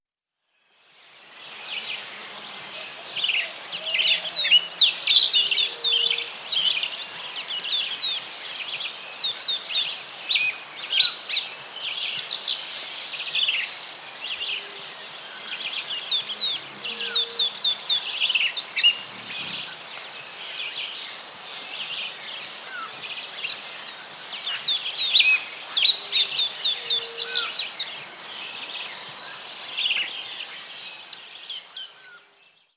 • hummingbirds in tropical rainforest.wav
hummingbirds_in_tropical_rainforest_fYD.wav